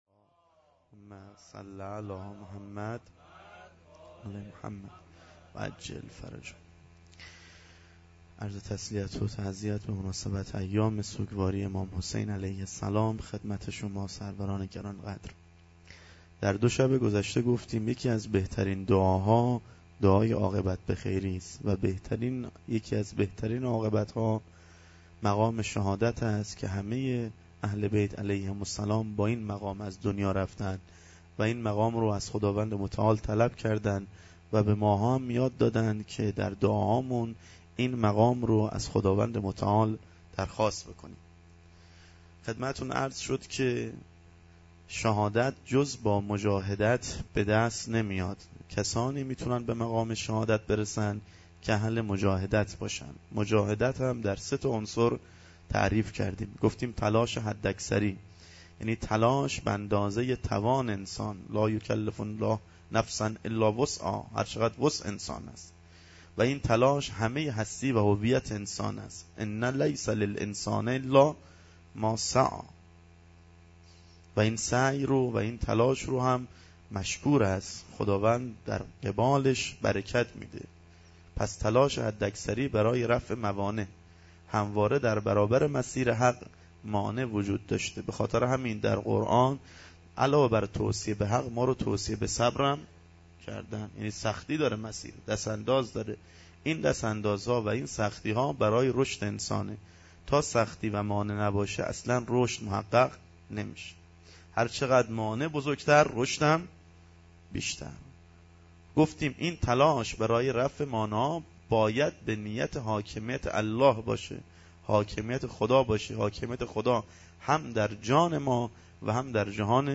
شب سوم محرم الحرام97